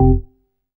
ORGAN-12.wav